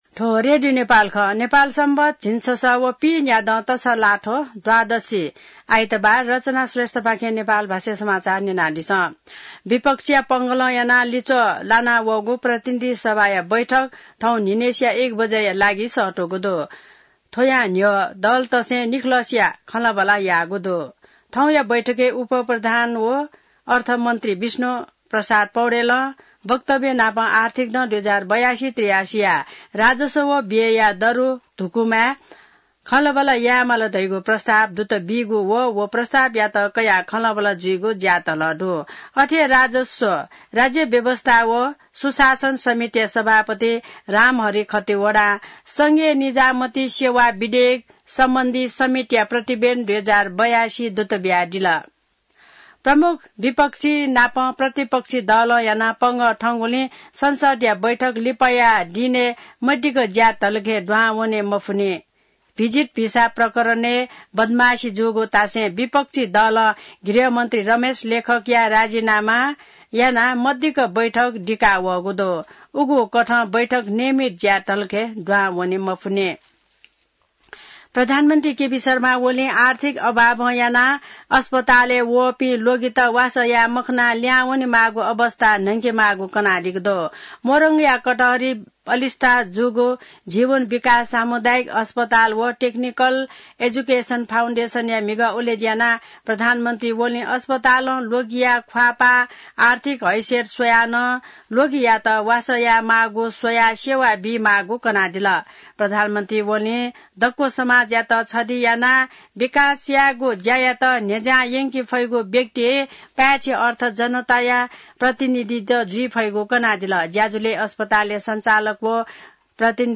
नेपाल भाषामा समाचार : २५ जेठ , २०८२